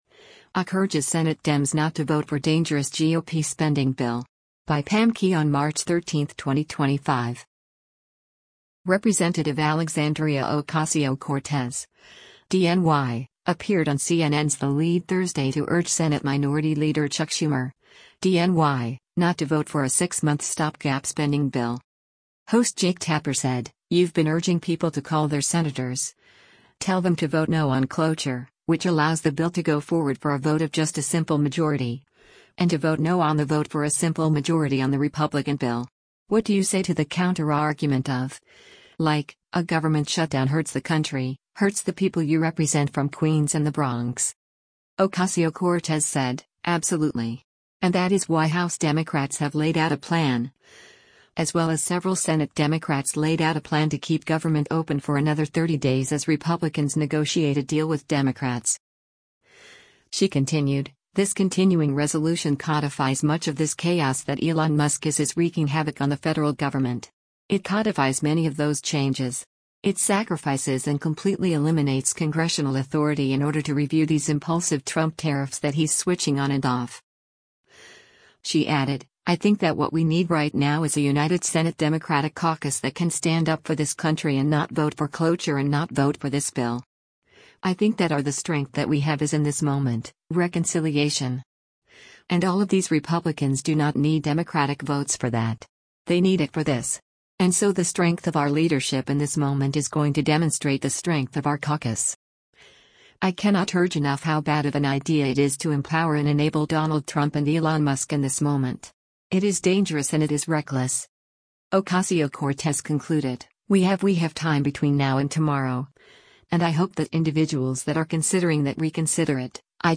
Representative Alexandria Ocasio-Cortez (D-NY) appeared on CNN’s “The Lead” Thursday to urge Senate Minority Leader Chuck Schumer (D-NY) not to vote for a six-month stopgap spending bill.